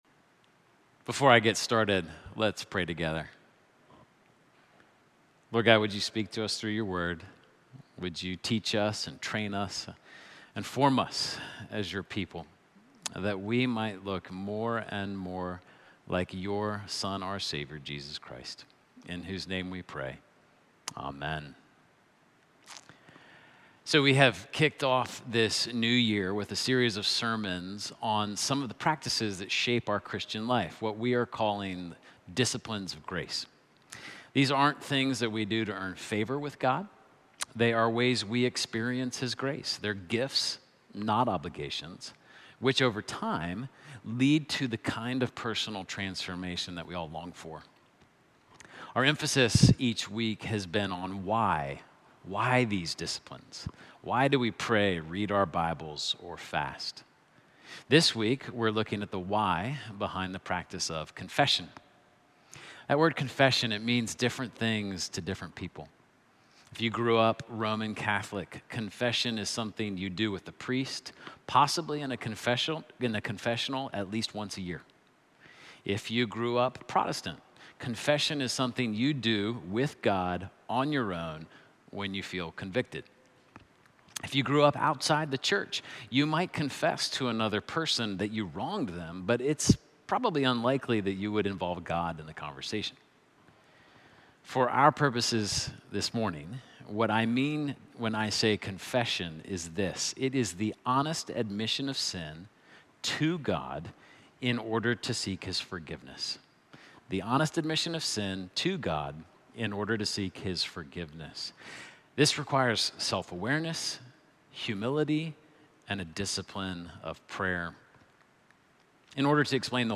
Sermons - Holy Trinity Anglican Church
Snowday-Sermon-1.mp3